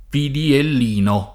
piddiellino [ piddiell & no ] (meglio che pidiellino [ id. o pidiell & no ])